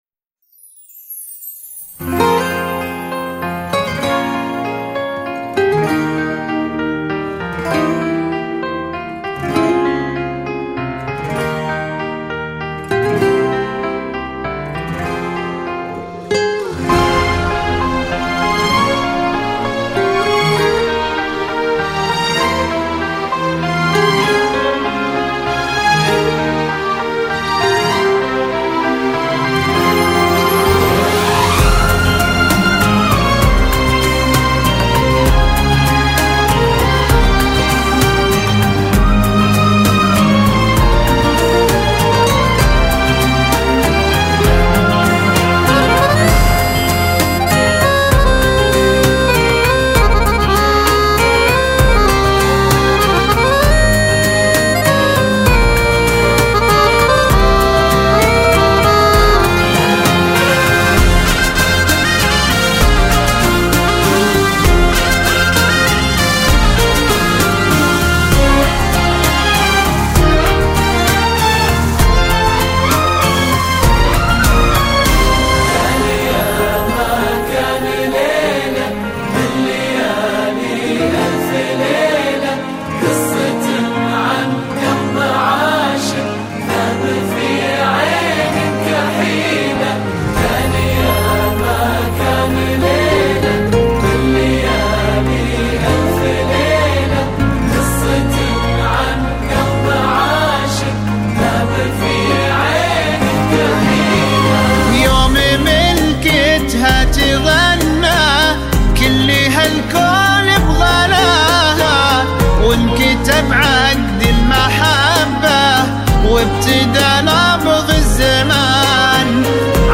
بذكاء الاصطناعي
زفات السعودية